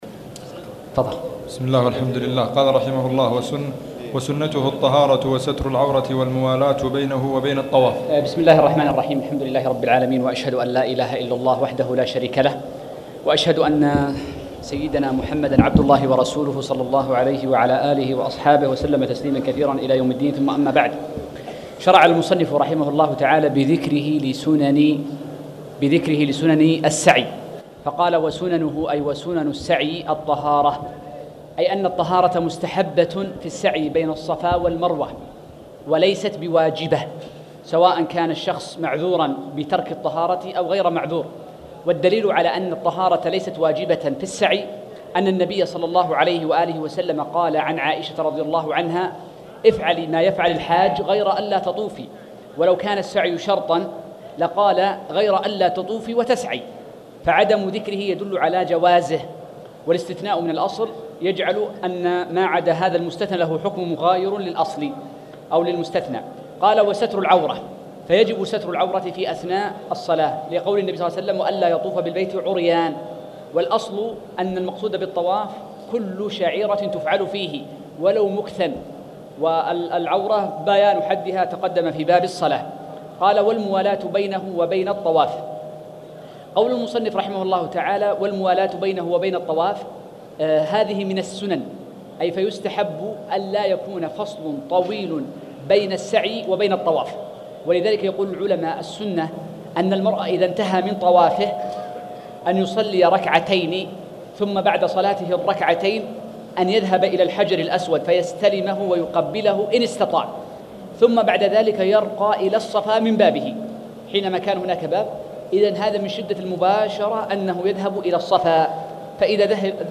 تاريخ النشر ٥ ذو الحجة ١٤٣٧ هـ المكان: المسجد الحرام الشيخ